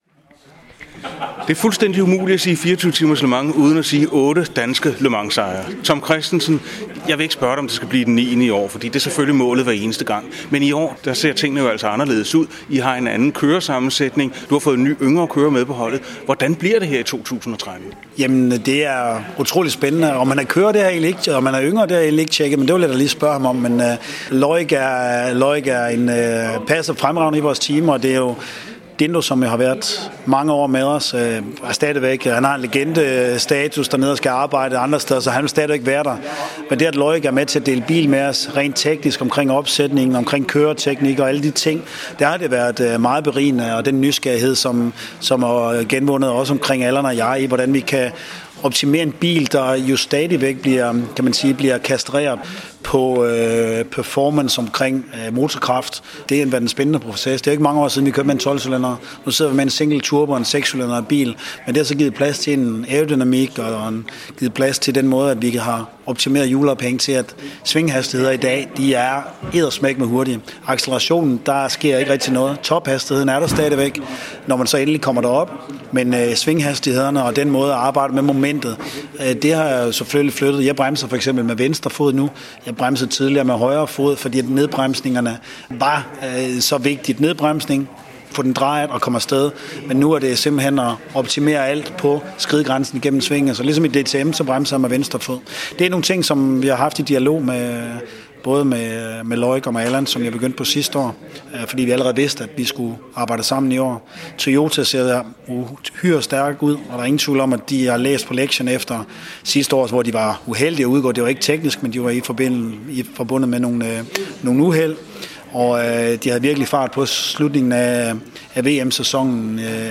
Interview i Motorradioen med Tom Kristensen op til Le Mans 2013
Tom Kristensen interview w.mp3